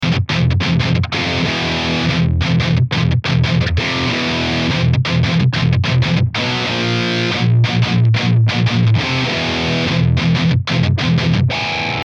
вот простой пример )) две краснушных гитары , по формфактору практически одинаковых , с флойдами но только одна со сквозным грифом - есп ,а другая с болченым - ибанез И датчики совершенно одинаковые , емг 81 (которые как широко известно всем диванным икспердам - делают все гитары одинаковыми ) Разница в атаке - космическая Вложения palm esp.mp3 palm esp.mp3 473,5 KB · Просмотры: 2.556 palm ibanez.mp3 palm ibanez.mp3 399,1 KB · Просмотры: 2.510